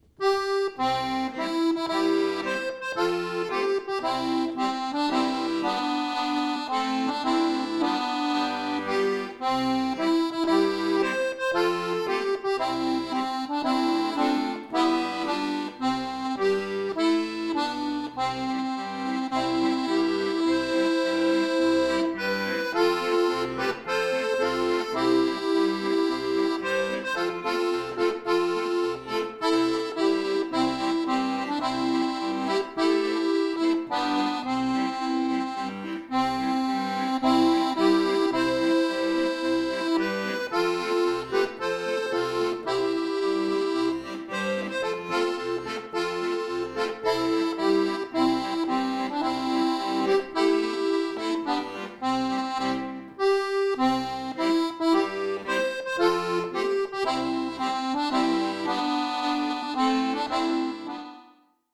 Einfach bis mittel
Marsch, Seemannslied, Shanty